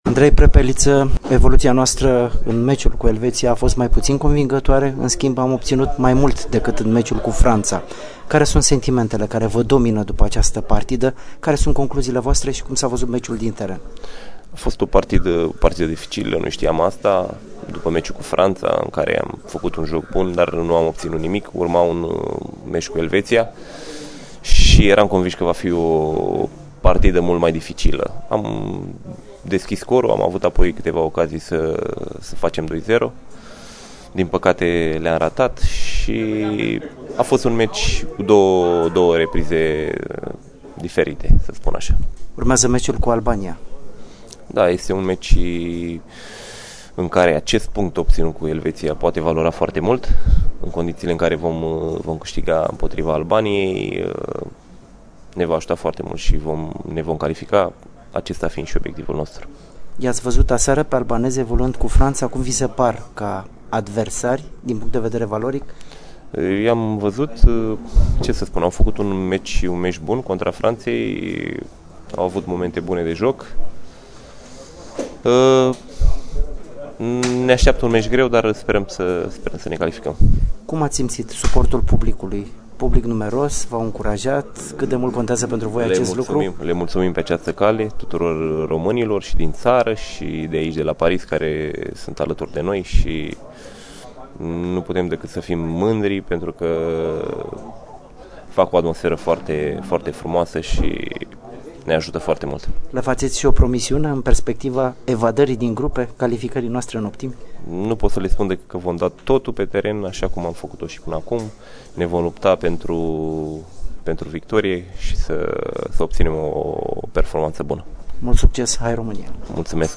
EXCLUSIV: Interviu cu Andrei Prepeliță după meciul cu Elveția (audio)